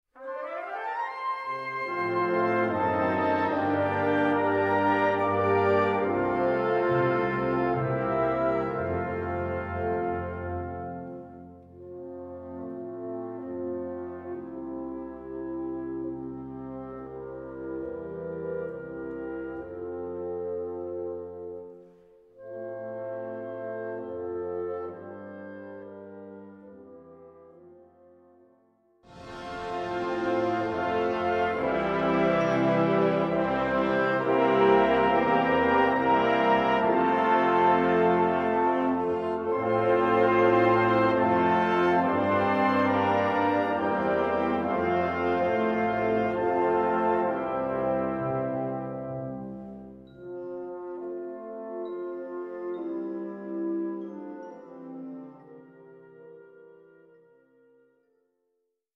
Gattung: Hymn Tune
Besetzung: Blasorchester